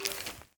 Minecraft Version Minecraft Version 25w18a Latest Release | Latest Snapshot 25w18a / assets / minecraft / sounds / mob / bogged / ambient3.ogg Compare With Compare With Latest Release | Latest Snapshot